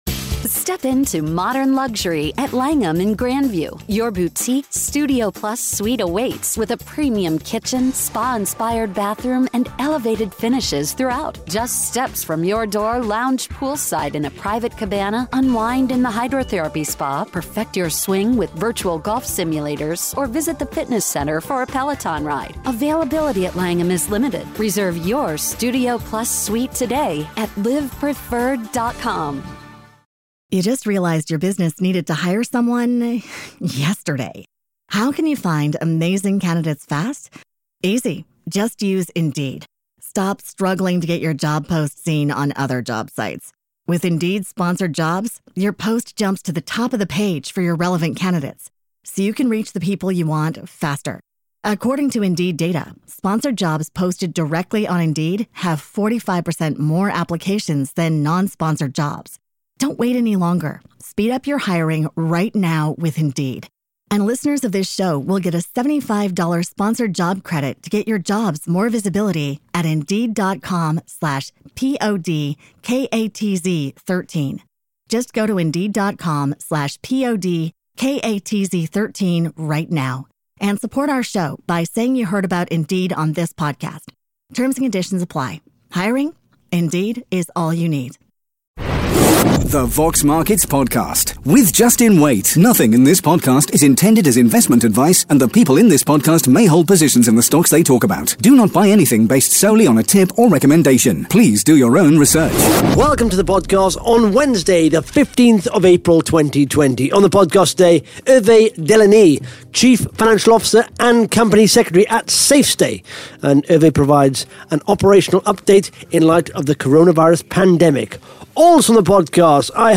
(Interview starts at 11 minutes 40 seconds)